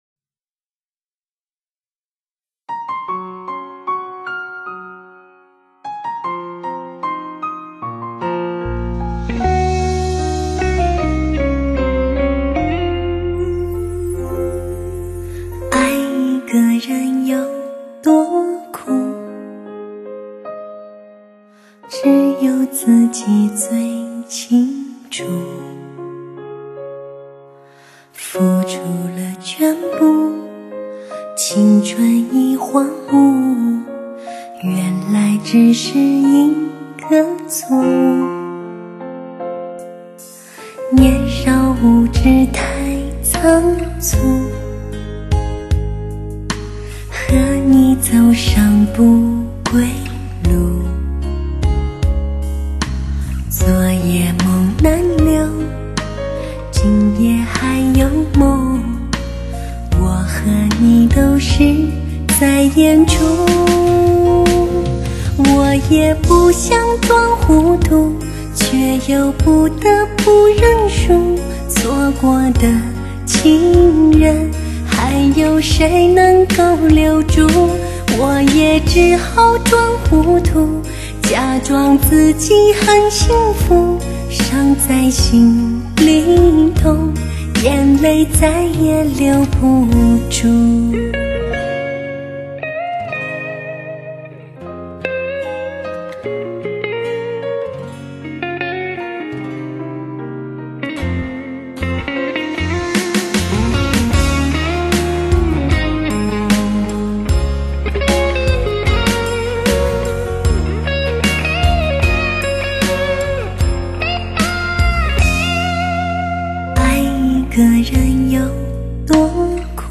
高品质环绕女声 点燃麦克风发烧味道
强势高清解码还原真声 带来超乎想象的震撼级高临场感